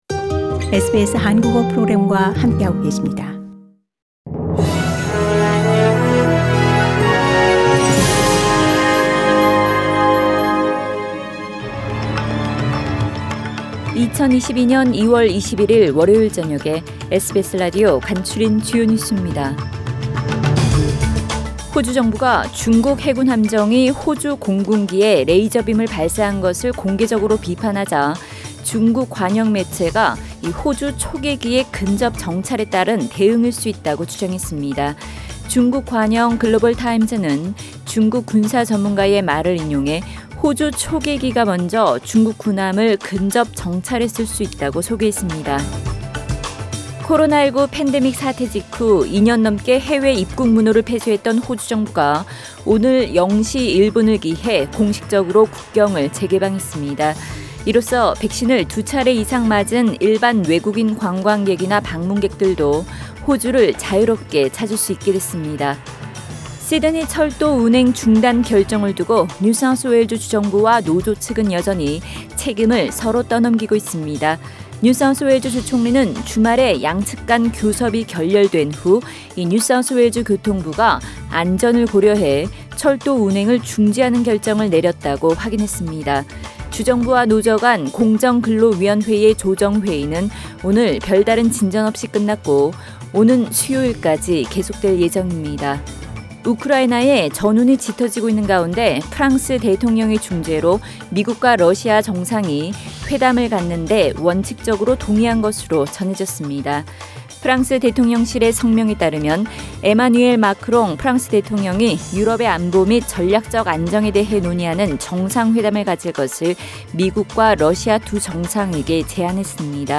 SBS News Outlines…2022년 2월 21일 저녁 주요 뉴스